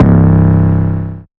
x [808].wav